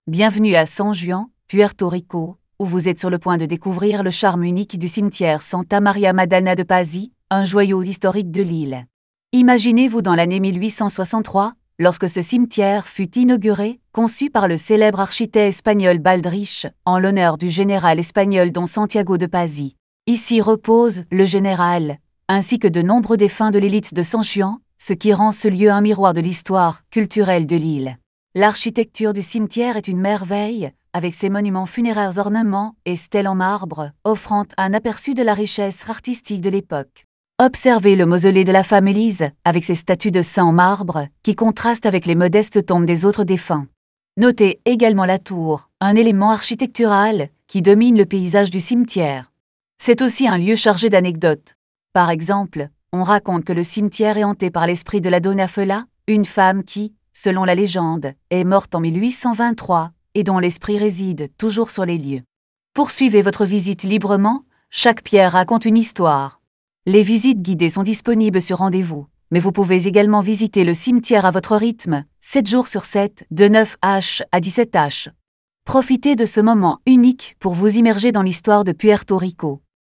karibeo_api / tts / cache / 7c9acf51af19bd5023e332cfc054a38f.wav